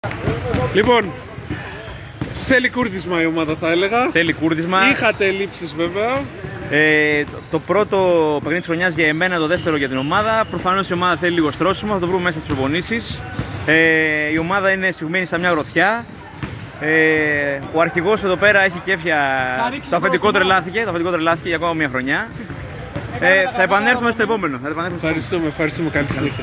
GAMES INTERVIEWS
Παίκτης Suits